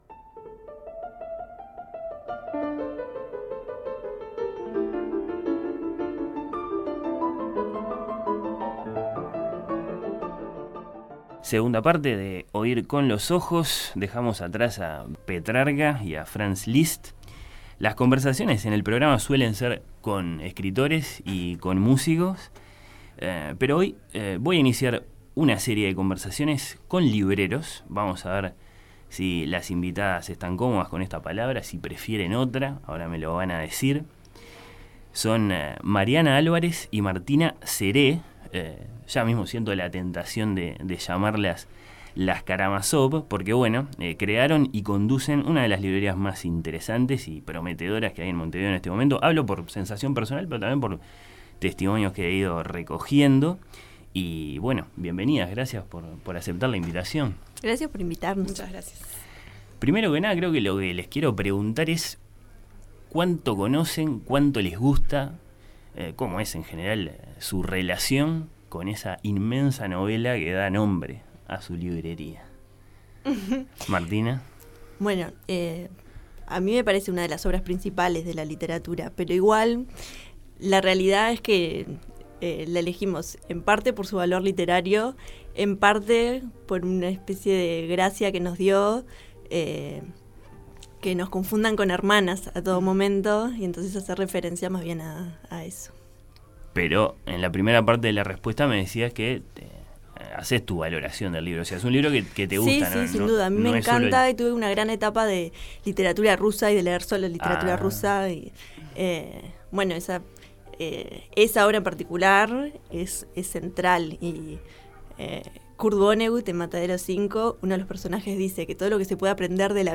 Conversaciones con libreros.